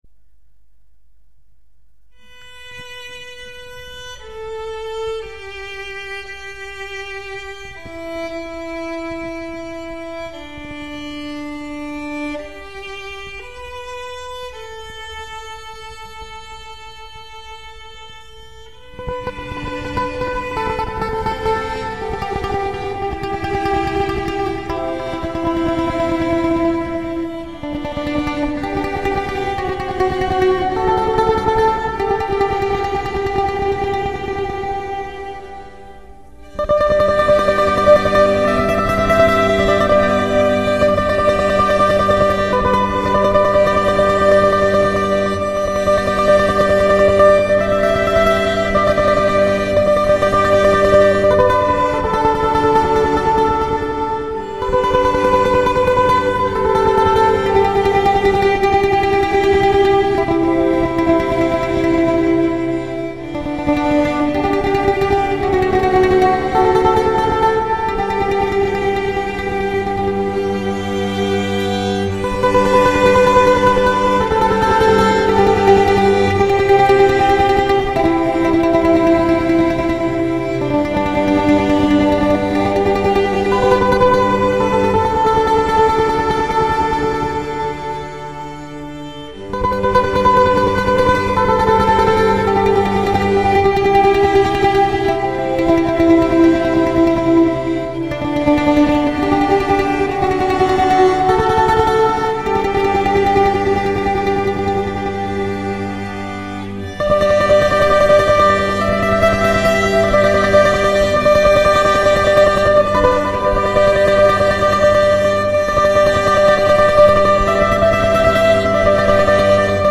Drei Töne auf der Mandoline, und Italien ist im Raum.
Ein Instrument, das nach Italien klingt